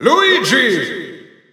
German Announcer announcing Luigi.
Luigi_German_Announcer_SSBU.wav